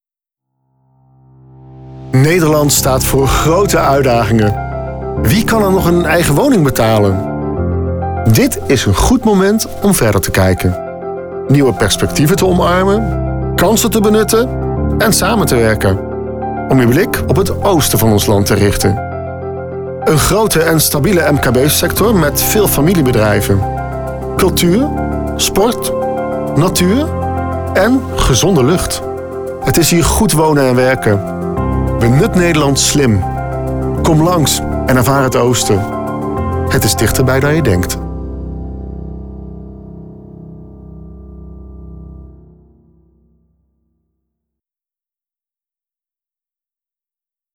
Een aantal voorbeelden van mijn voice-over opdrachten